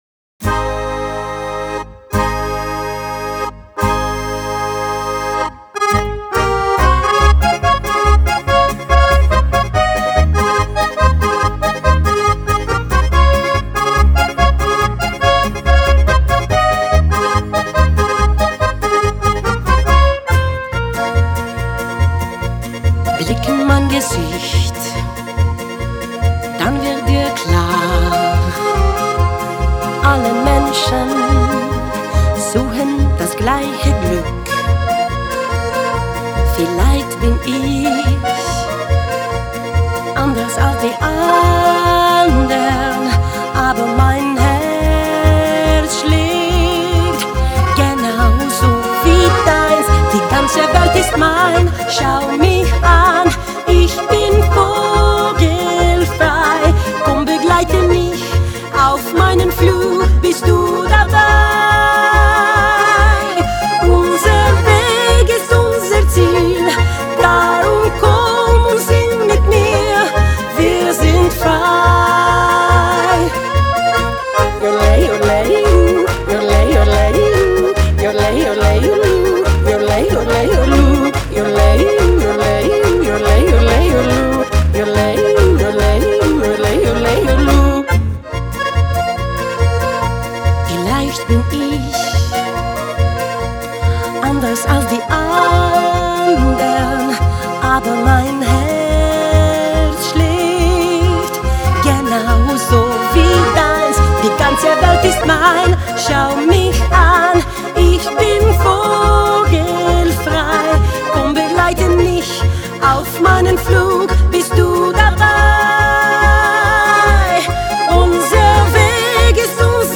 идеју употпуњује овом специфичном верзијом са јодловањем.